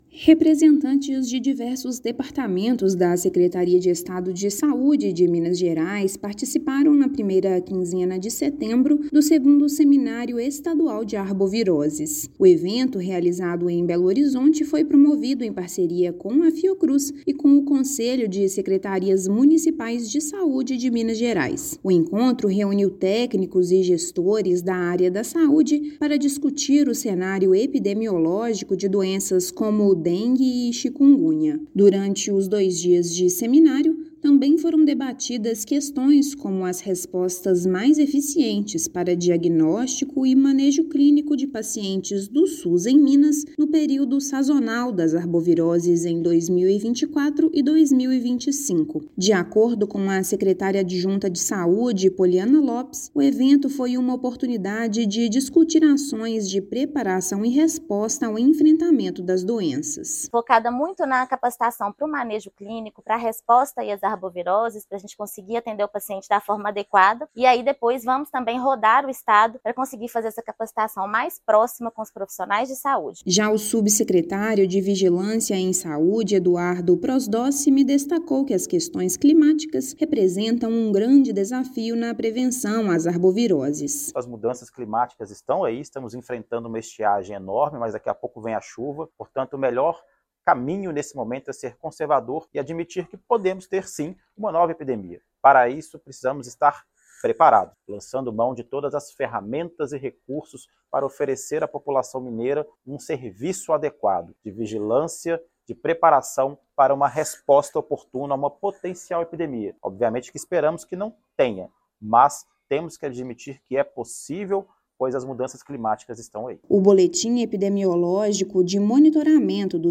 Durante primeiro dia do Seminário de Arboviroses, técnicos e gestores da saúde discutiram respostas que agilizem a assistência aos pacientes do SUS-MG. Ouça matéria de rádio.